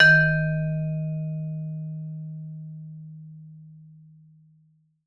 Index of /90_sSampleCDs/Sampleheads - Dave Samuels Marimba & Vibes/VIBE CMB 1B